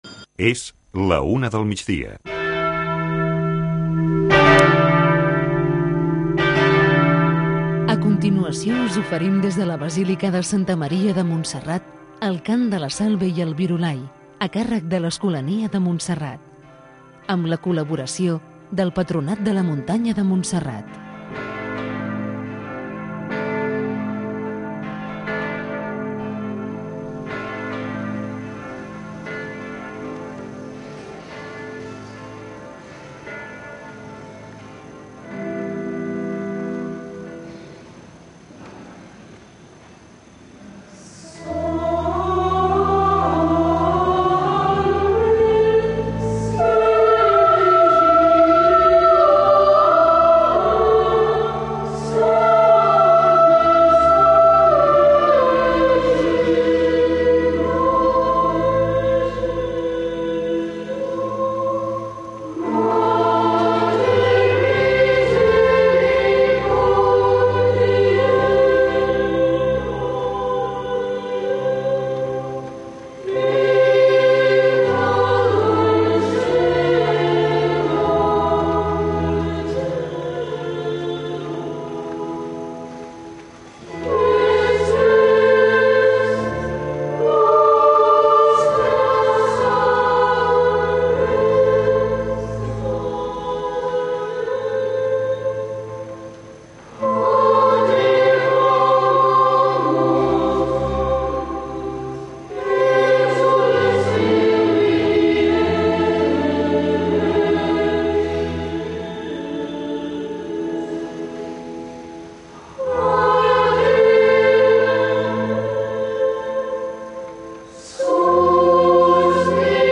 amb l'Escolania de Montserrat
L’escolania de Montserrat canta la Salve i el Virolai als peus de la Moreneta, com un moment de pregària al migdia, en el qual hi participen molts fidels, pelegrins i turistes que poden arribar omplir a vessar la basílica.